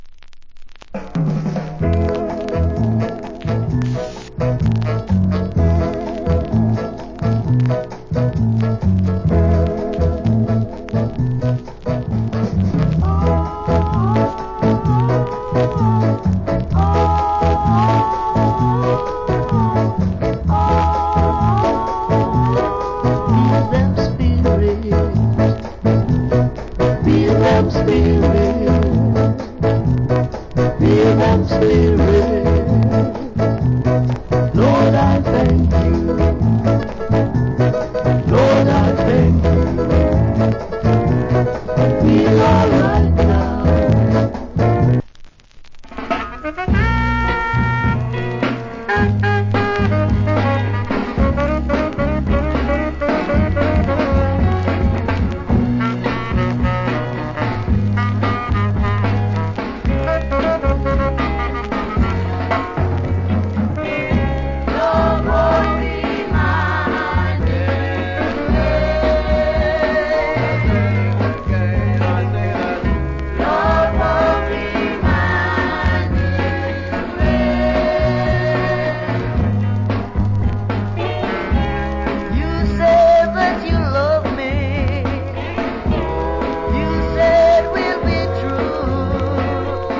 category Ska
Ska Vocal.
/ Great Ballad Vocal.